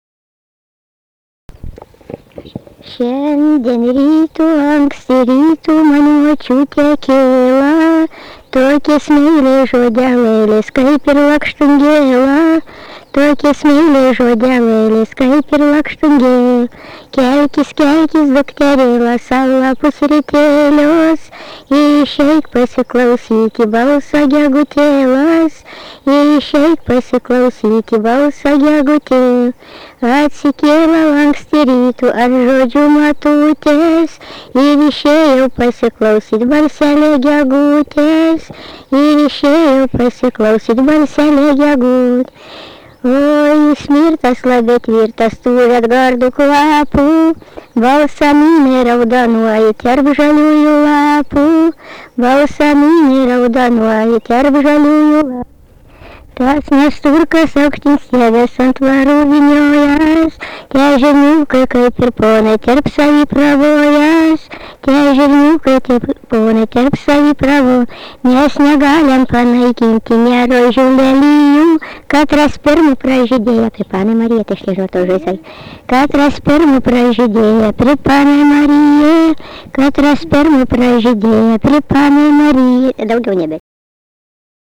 daina
Deikiškiai
vokalinis